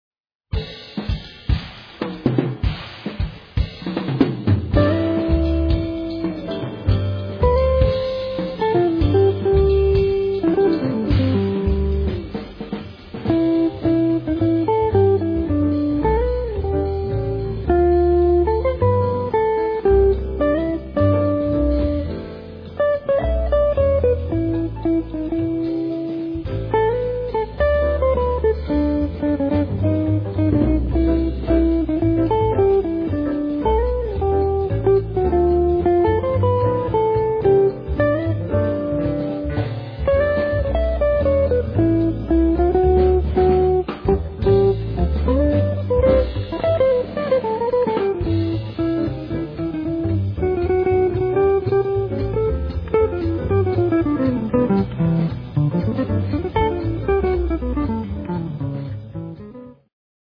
guitar
keyboards
bass
drums